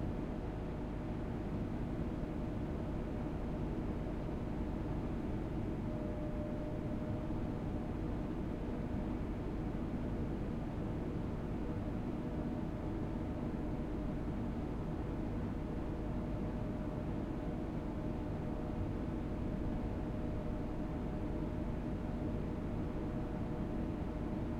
apartmentLoop.ogg